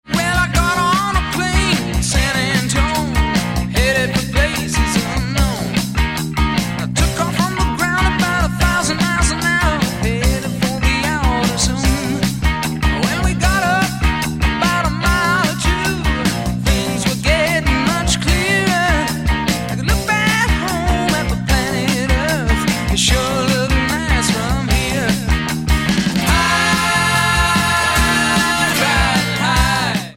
guitar, synthesizer, vocals
drums, percussion
guitar, keyboards, vocals
Album Notes: Recorded at Can-Base Studios, Vancouver, Canada